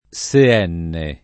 [ S e- $ nne ]